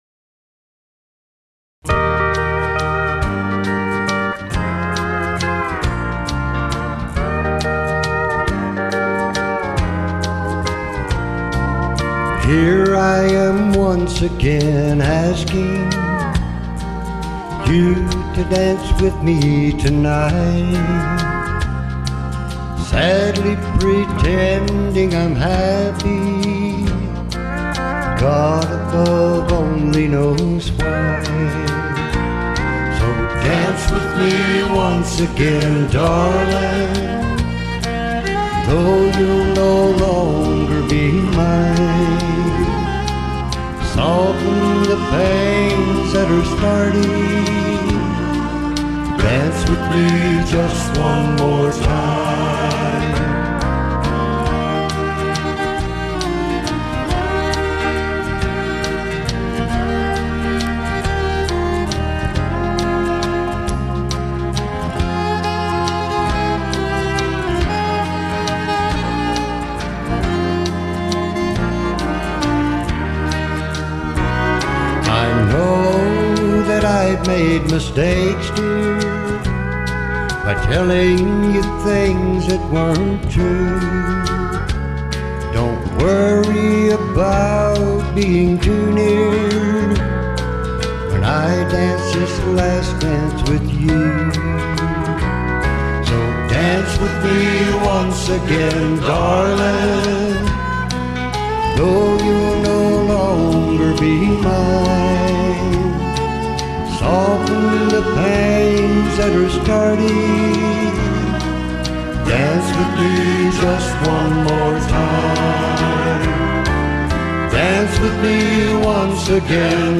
Classic Country, Old Rock N' Roll, Country Gospel, and Blues